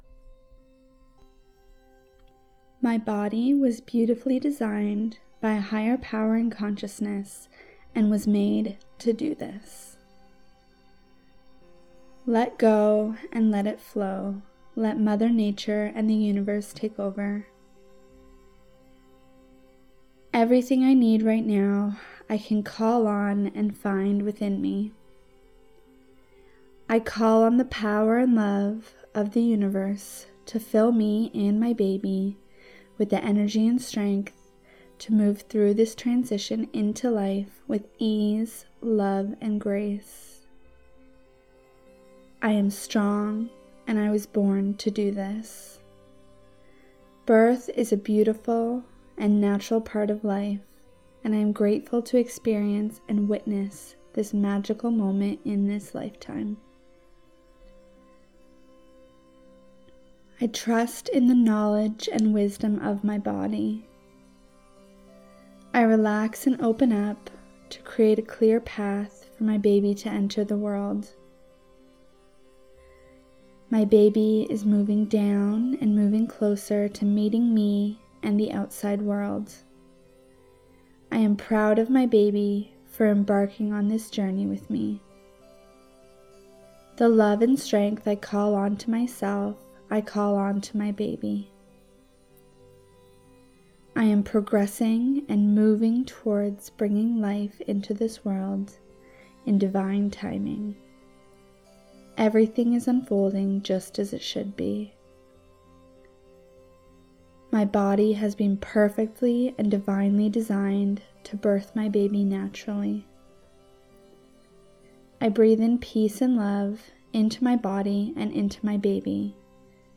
Birthing-Affirmations-With-BG.mp3